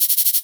FK092PERC1-R.wav